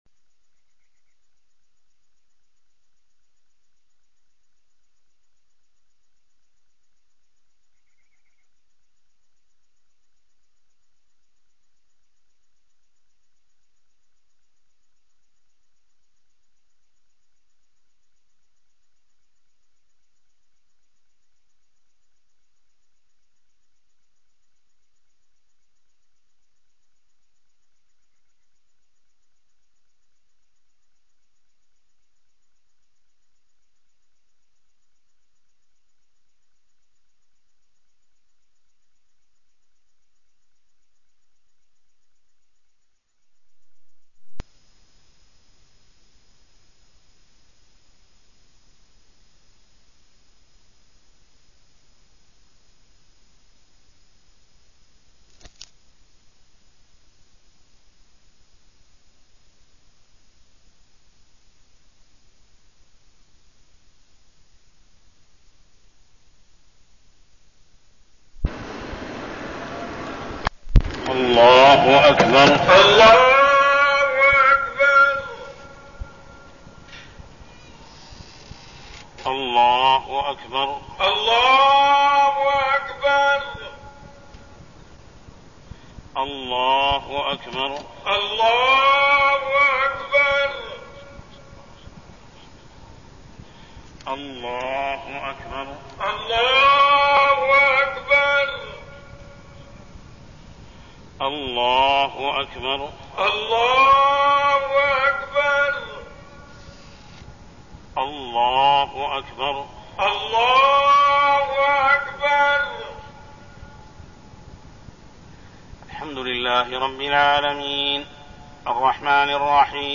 تاريخ النشر ٤ جمادى الأولى ١٤١٢ هـ المكان: المسجد الحرام الشيخ: محمد بن عبد الله السبيل محمد بن عبد الله السبيل الإبتلاء بالجدب